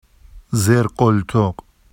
[zerɢoltoɢ] n armpit